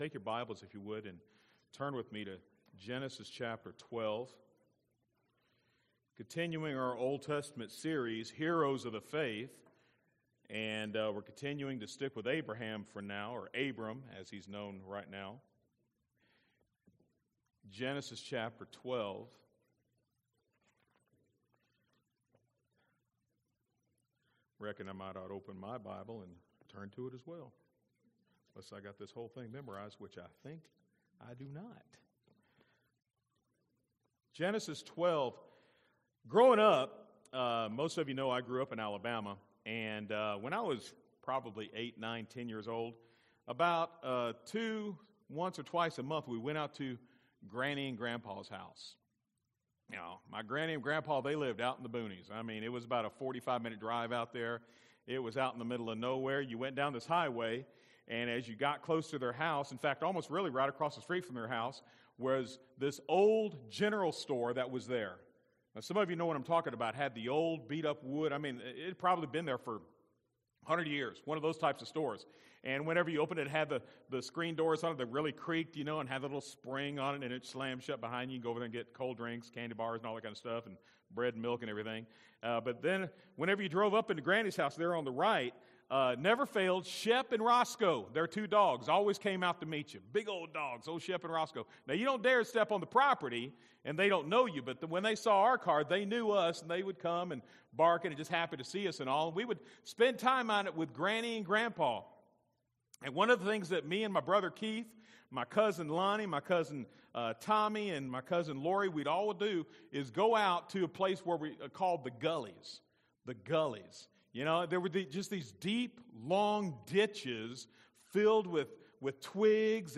A message from the series